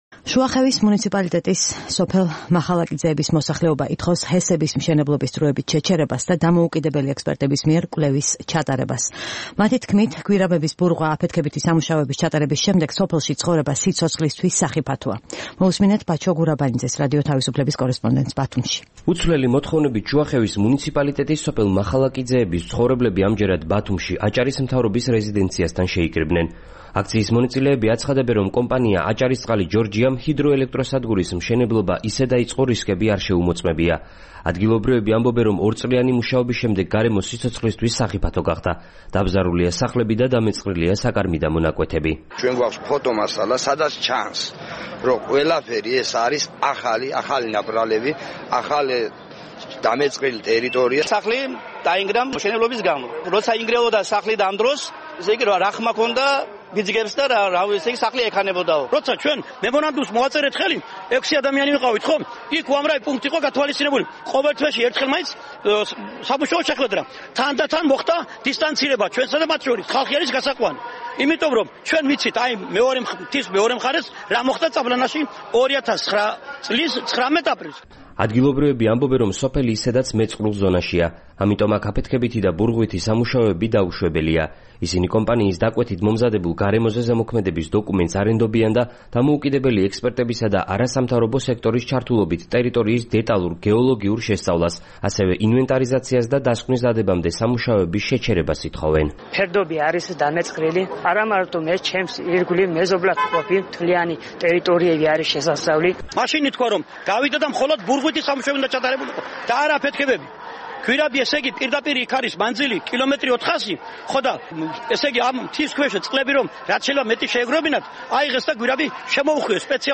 რეპორტაჟი აჭარიდან